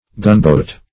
Gunboat \Gun"boat`\, n.